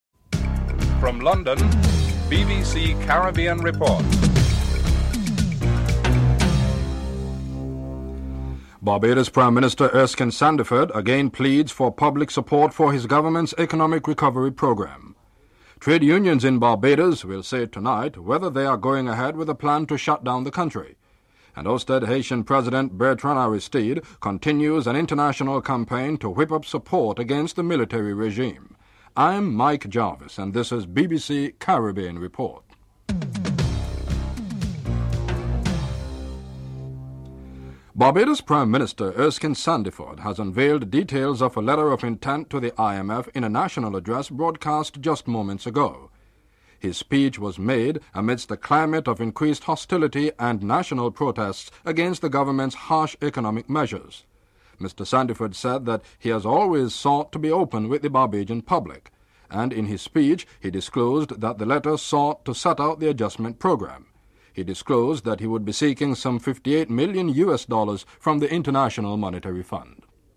1. Headlines (00:00-00:36)
In a national broadcast, the Prime Minister of Barbados unveiled details of a letter of intent to the IMF. Excerpt from Erskine Sandiford’s speech (00:37-02:03)